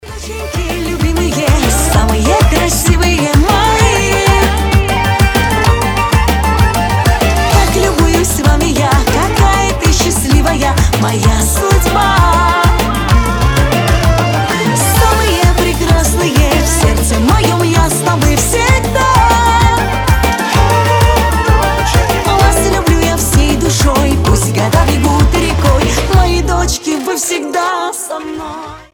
громкие
восточные
быстрые
кавказские
шансон